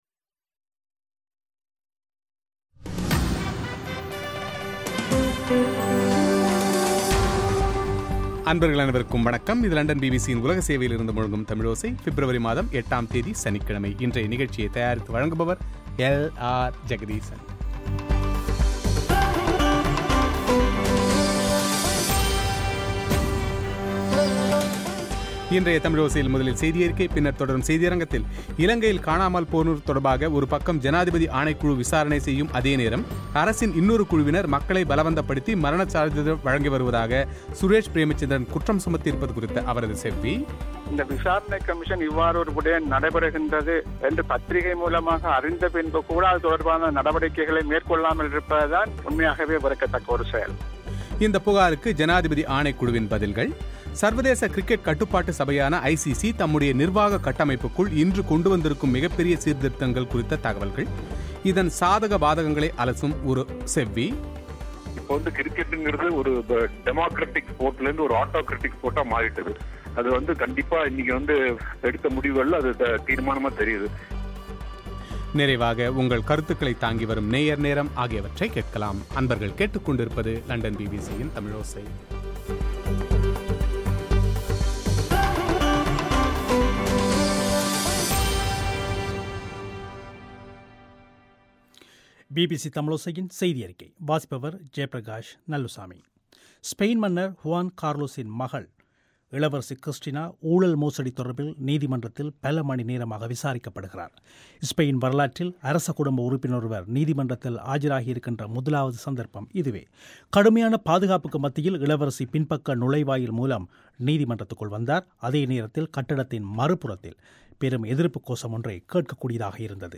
இலங்கையில் காணாமல்போனோர் தொடர்பாக ஒருபக்கம் ஜனாதிபதி ஆணைக்குழு விசாரணை செய்யும் அதேநேரம், அரசின் இன்னொரு குழுவினர் மக்களை பலவந்தப்படுத்தி மரணச் சான்றிதழ் வழங்கிவருவதாக சுரேஷ் பிரேமச்சந்திரன் குற்றம் சுமத்தியிருப்பது குறித்து அவரது செவ்வி;